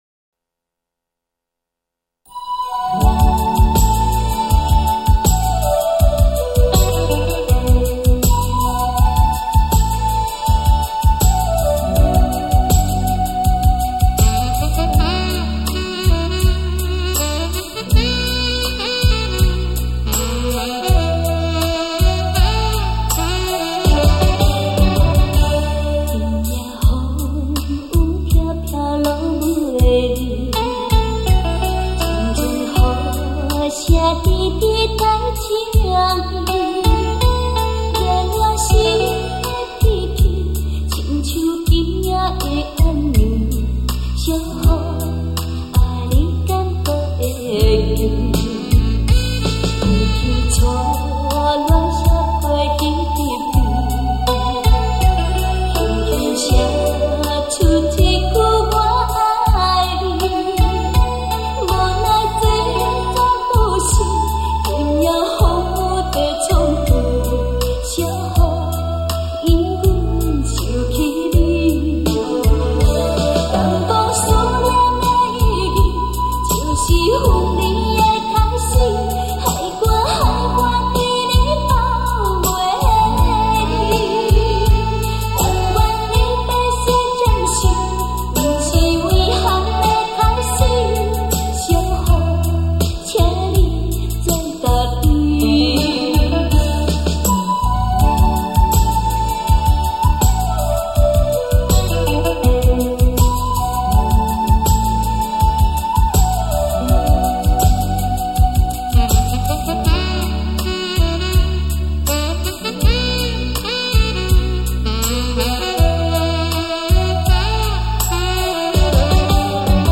[2004-12-14] 闽南歌 《小雨》